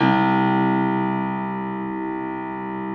53g-pno02-C0.wav